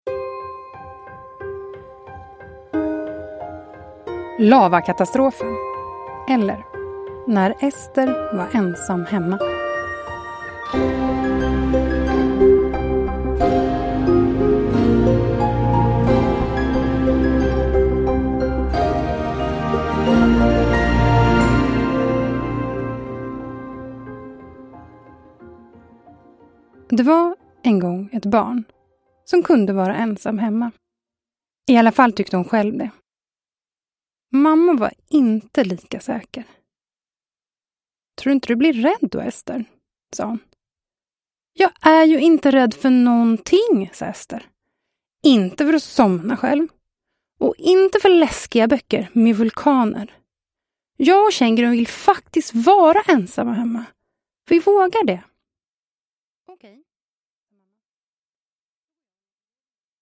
Lavakatastrofen eller när Ester var ensam hemma – Ljudbok